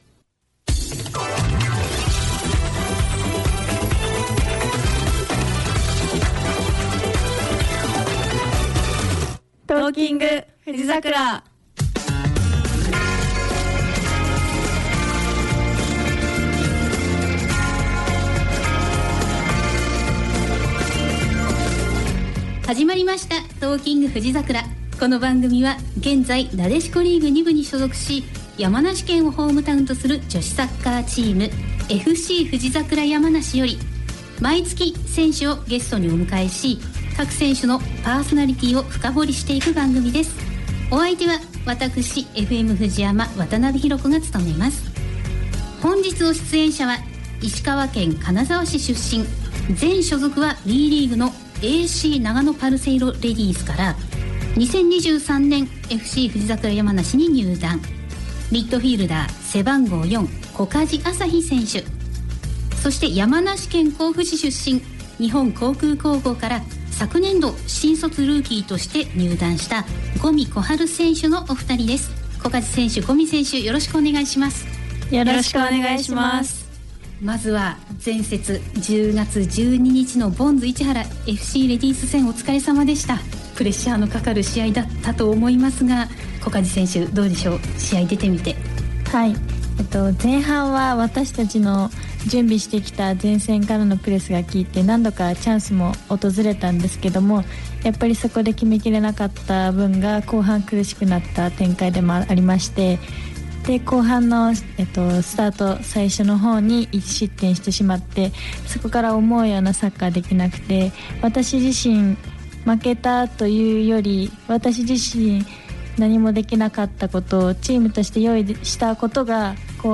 2025年10月16日(木)放送分「Talking！ふじざくら」アーカイブを公開！
今週末10月18日(土)に開催される、2025プレナスなでしこリーグ2部第22節（最終節）への意気込み、前節の振り返り、 さらにその先に続く皇后杯について、お伺いしました。 もちろん、いつも通り普段のお仕事のお話、お互いの良いところを褒め合って頂いたり、 ほのぼのと楽しいトークも盛りだくさんです。
（ラスト曲は版権の都合上カットしています。ご了承ください。）